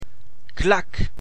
Clac
clac.mp3